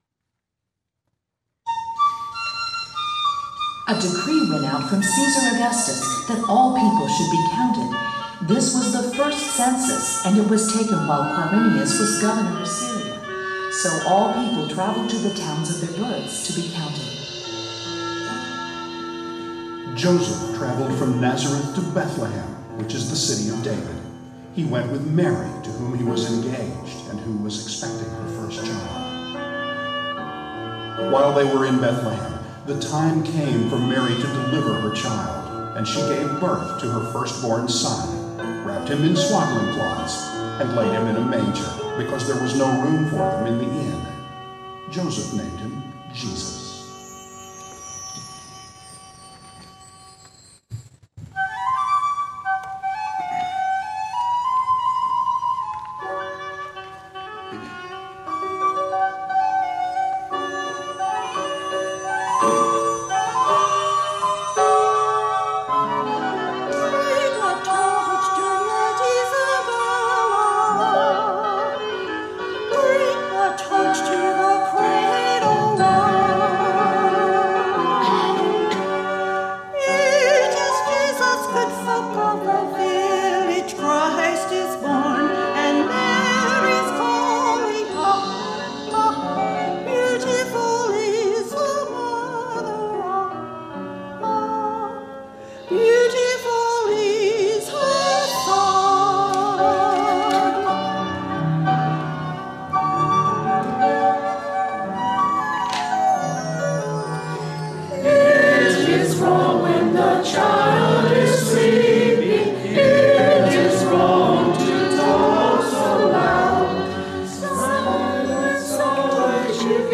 2022 Christmas Cantata | LibertyBaptistChurch
Service Audio
The LBC Choir Present Christmas Cantata 2022 Come to the Manger